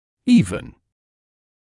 [‘iːvn][‘иːвн]плоский, ровный; выравнивать; даже